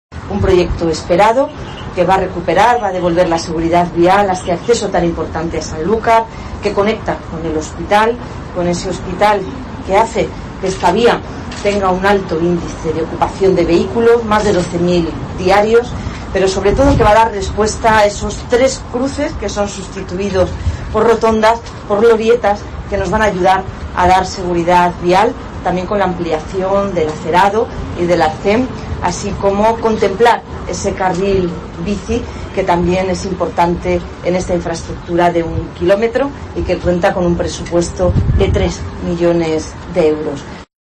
Escucha aquí a Marifrán Carazo, consejera de Fomento, Articulación del Territorio y Vivienda